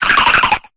Cri de Balbuto dans Pokémon Épée et Bouclier.